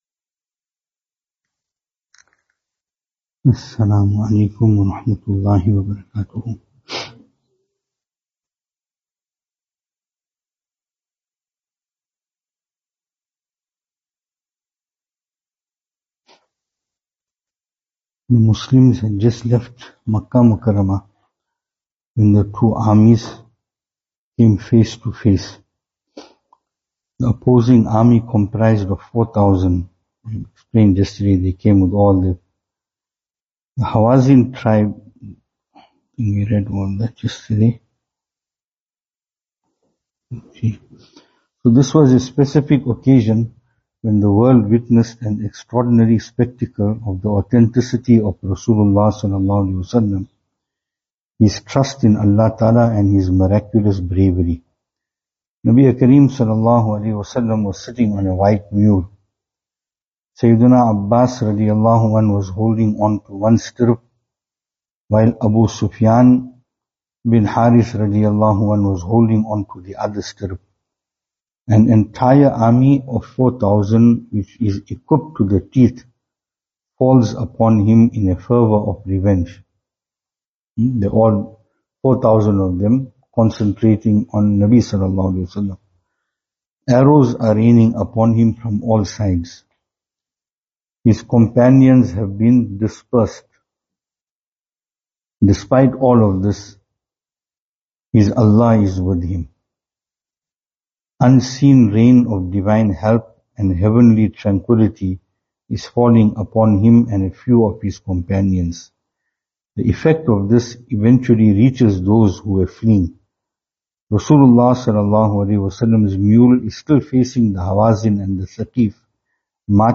Advices and Zikrullah Venue: Albert Falls , Madressa Isha'atul Haq Series: Ramadaan Service Type: Ramadaan Topics: Ramadaan , Zikr « Inculcating Taqwa, Laylatul Ja’izah and Sadaqatul Fitr 28th Ramadhaan.